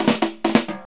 FILL 1   114.wav